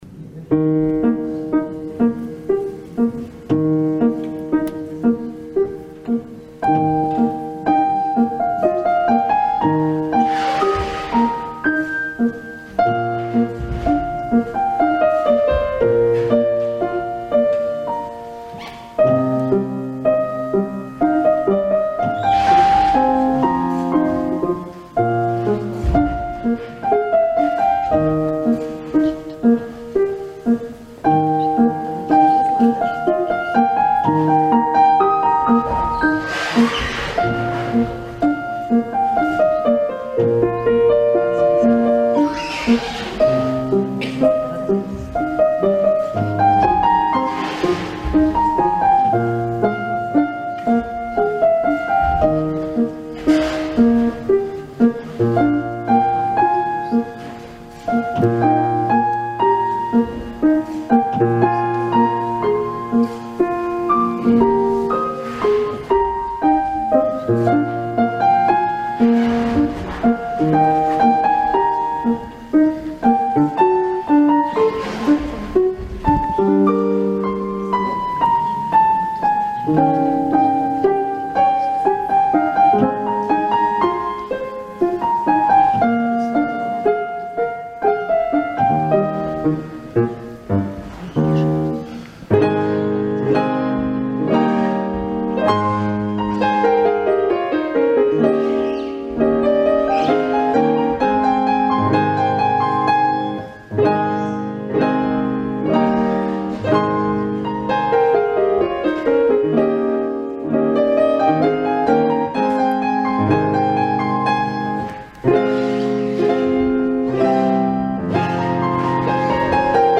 Друзья, пожалуйста, помогите определить название этого танца , исполненного на фортепиано, что-то очень знакомое, не пугайтесь посторонних звуков, это так танцуют под эту музыку...
Поднапрягся,и вспомнил - это Жорж Бизе, Сюита 2 Арлезианка, Менуэт, в переложении для фортепиано я раньше не слышал этой прекрасной музыки
opoznat-fortepiano.mp3